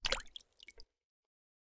Bullet Shell Sounds
generic_water_1.ogg